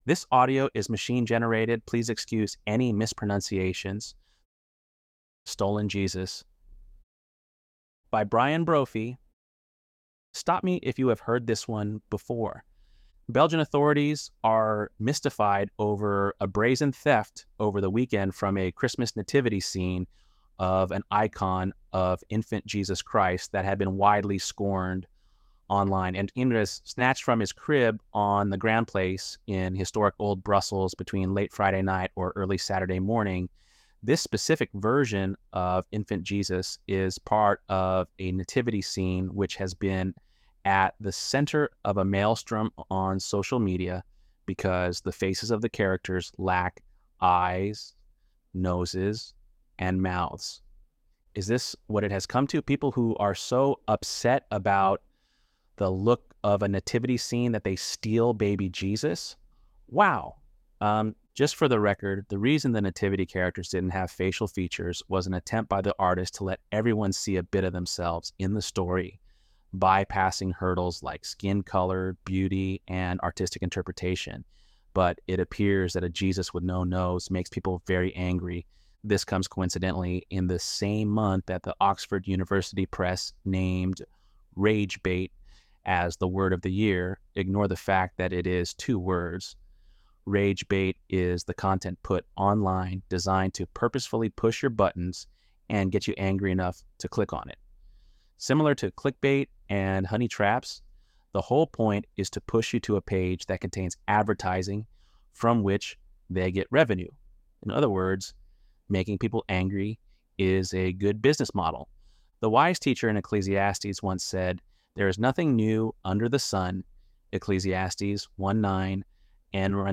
ElevenLabs_12_25.mp3